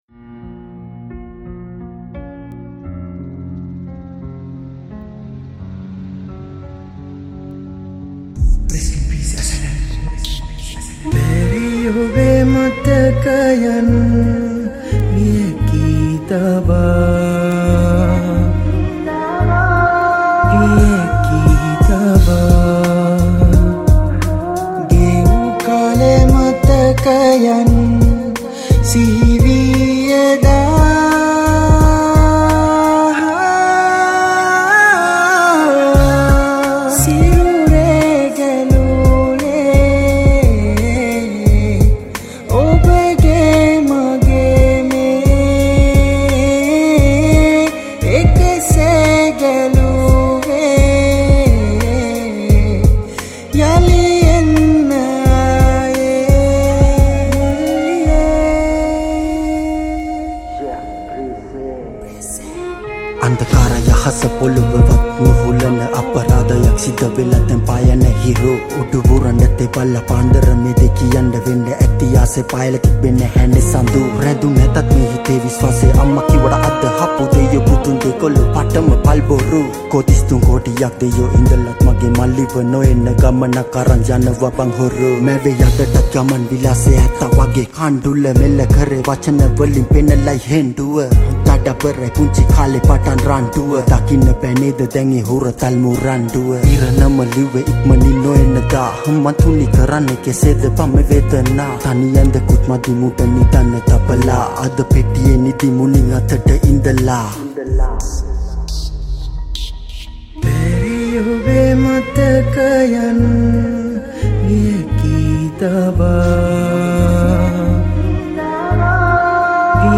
Category: Rap Songs